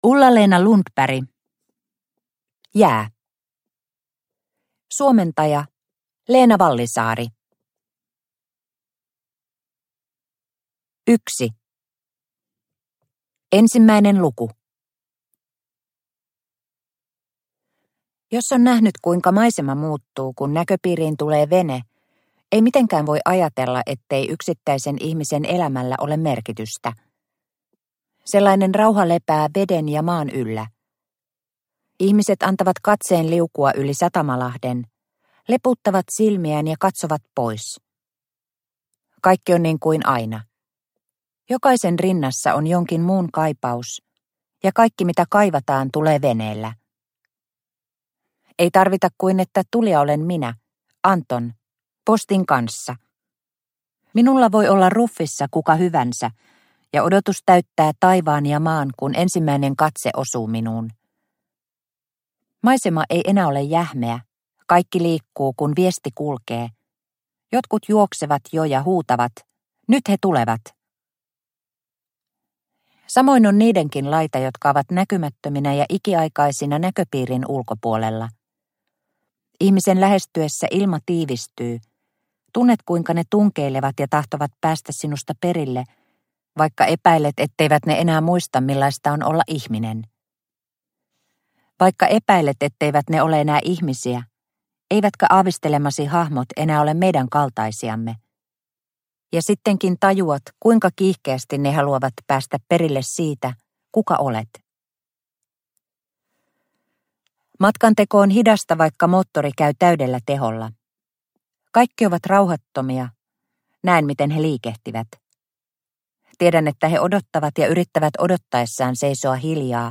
Jää – Ljudbok – Laddas ner
Uppläsare: